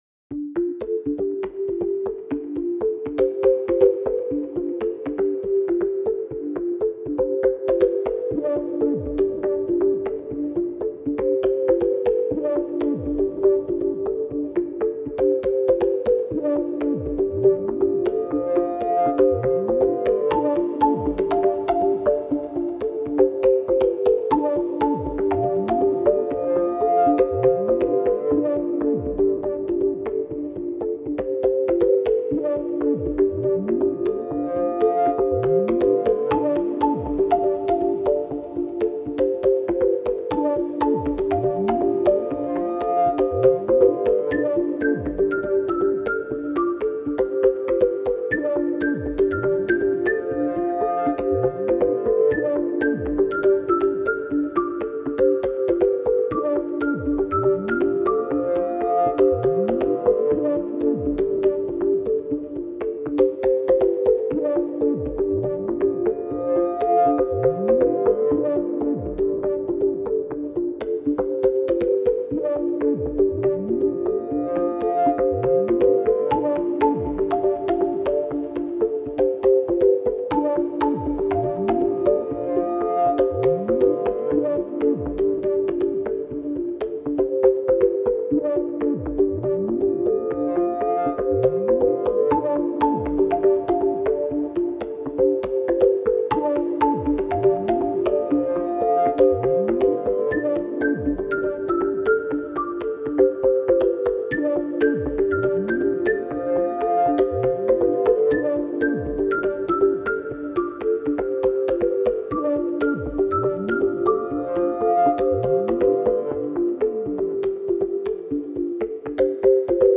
Synth sound